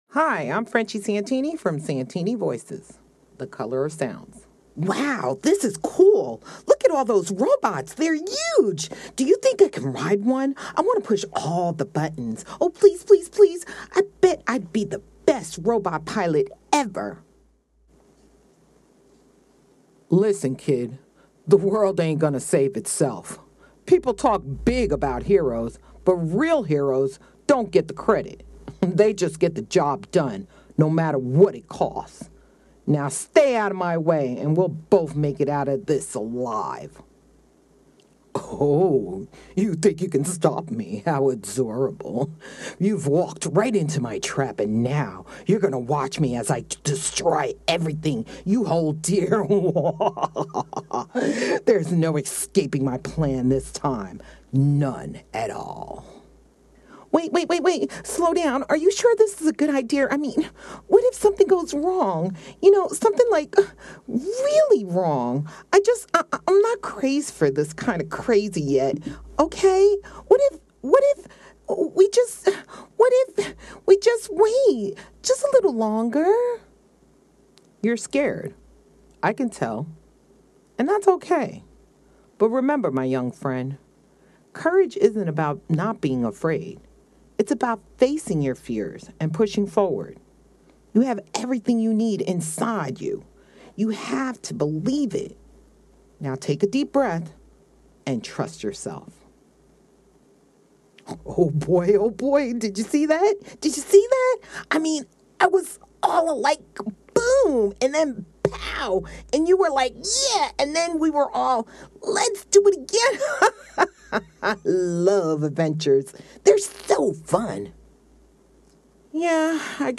Cartoon Reel 2025
Cartoon-Reel-Retake-April-2025.mp3